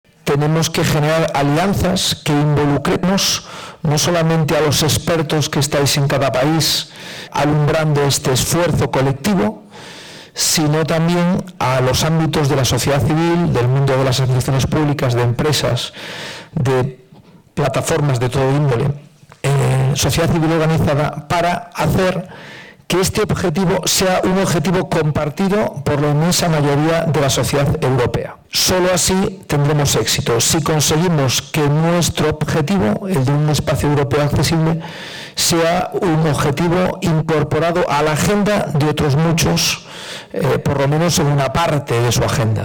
Fundación ONCE acogió, el pasado 7 de noviembre, un encuentro organizado por ‘AccessibleEU’ para impulsar el diálogo entre industria, administraciones públicas y sociedad civil con el objetivo de mejorar la accesibilidad.